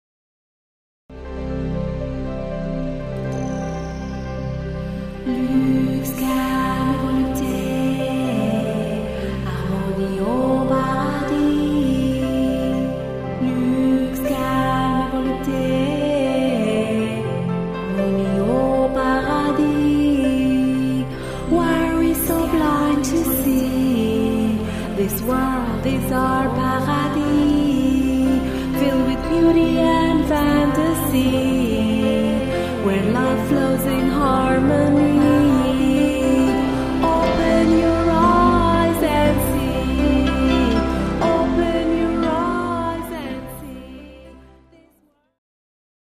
World (Inspirational)